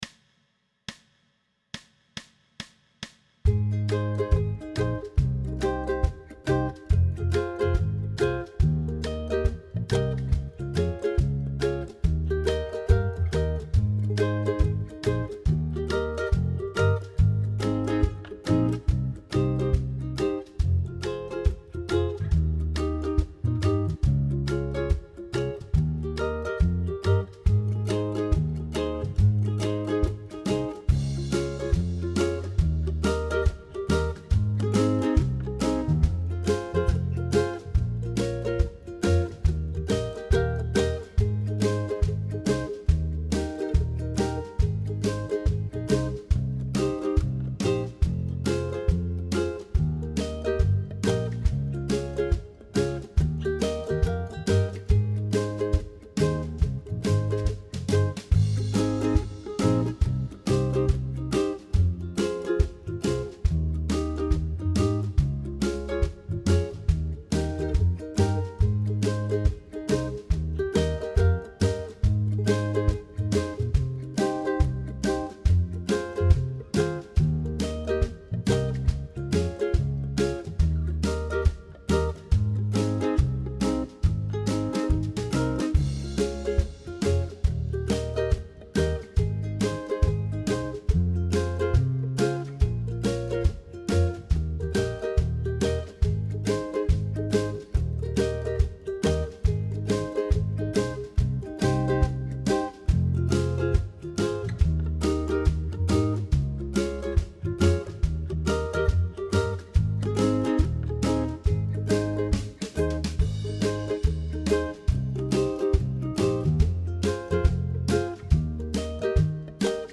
Pop style jam track with Ukulele Rhythm
Tempo: 140BPM
Key of Ab
ukulele-pop-140-ab.mp3